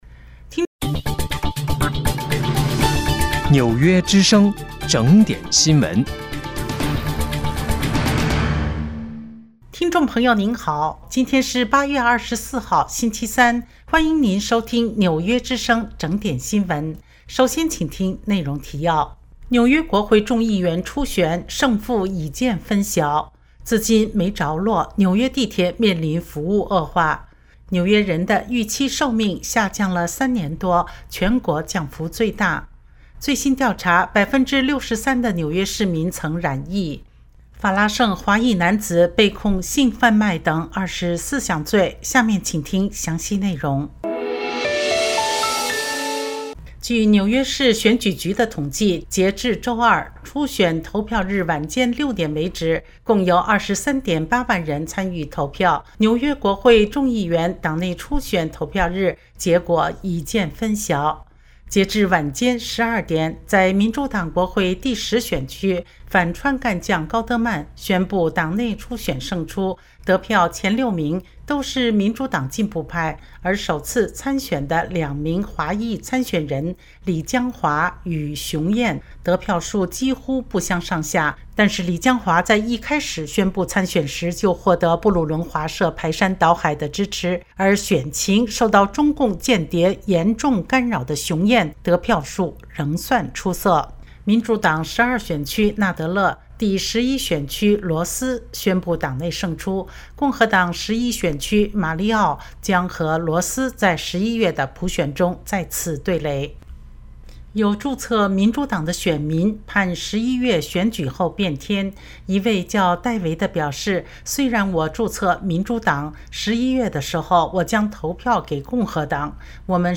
8月24日（星期三）纽约整点新闻